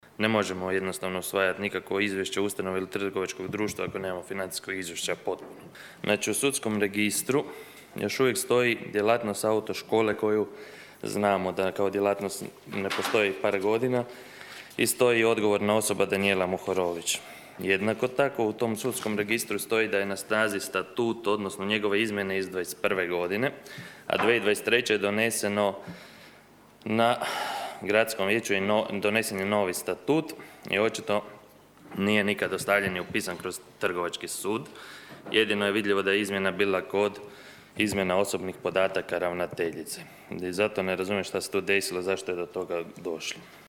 Gradsko vijeće Labina nije na današnjoj sjednici prihvatilo Izvještaj o radu Pučkog otvorenog učilišta za 2024. godinu.
Vijećnik s liste gradonačelnika Nino Bažon izrazio je nezadovoljstvo što vijećnicima nije podneseno i financijsko izvješće: (